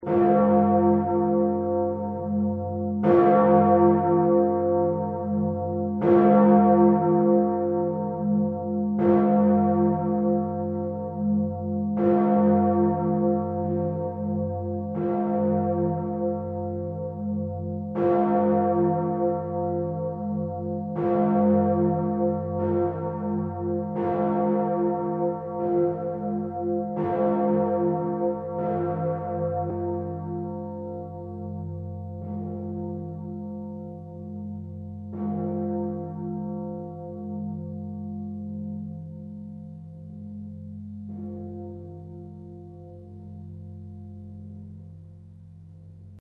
Glocken/Geläut im Mariendom Linz
Die größte Glocke des Geläuts ist die Immaculata-Glocke im dritten Turmstockwerk.
Immaculata-Glocke: F, 8.120 kg